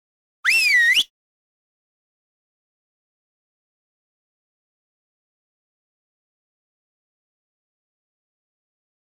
Звуки разбойников
Свист разбойников предупреждает о нападении